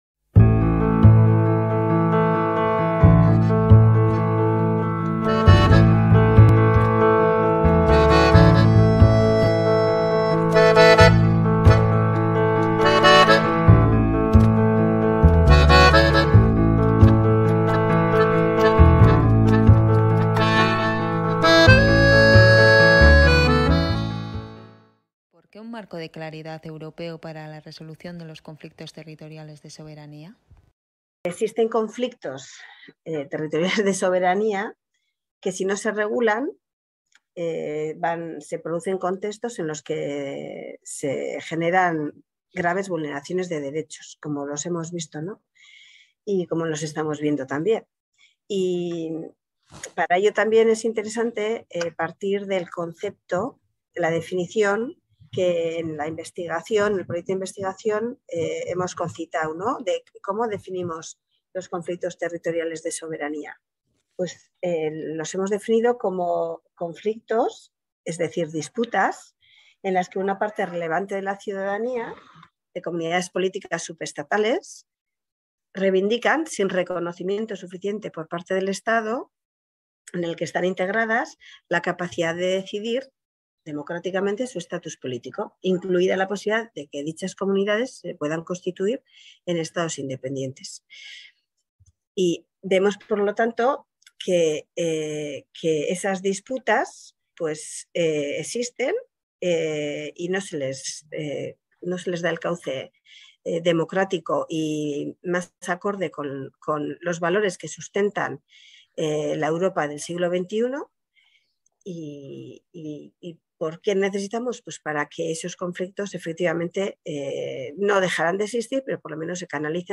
charlan en la sección Solasaldiak.